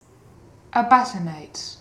Ääntäminen
Ääntäminen UK Haettu sana löytyi näillä lähdekielillä: englanti Käännöksiä ei löytynyt valitulle kohdekielelle.